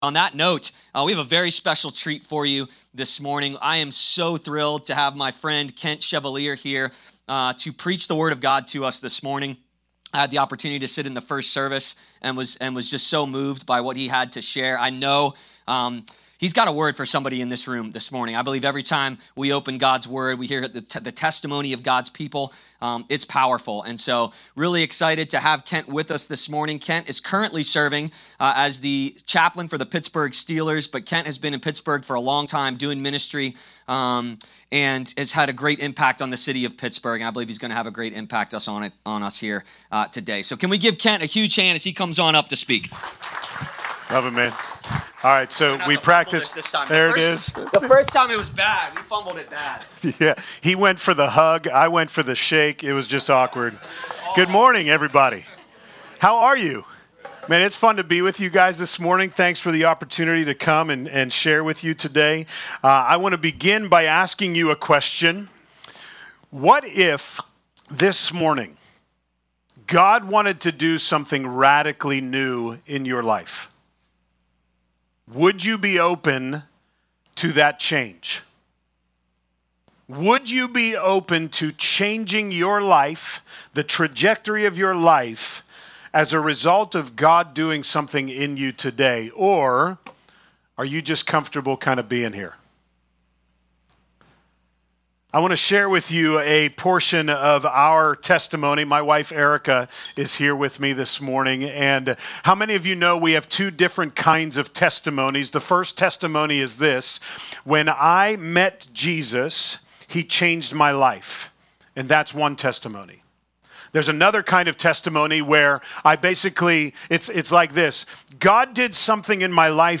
Do It Afraid | Guest Speaker